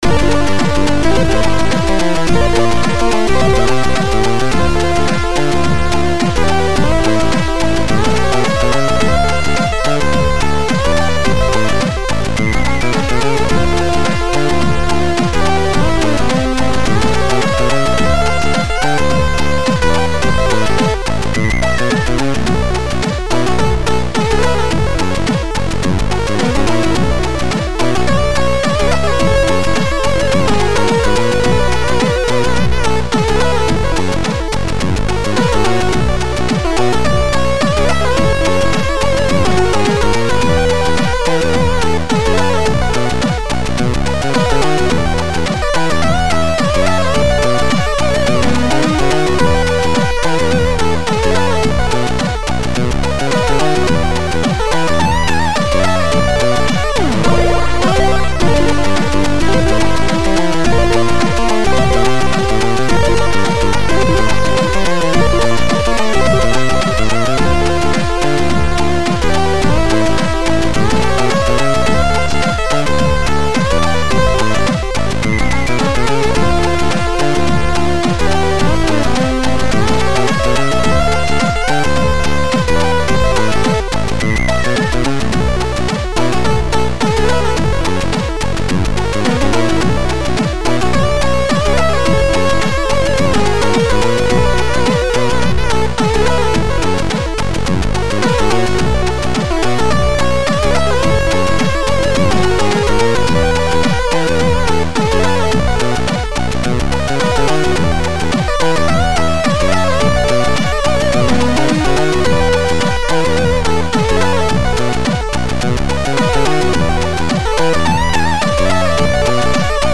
2 channels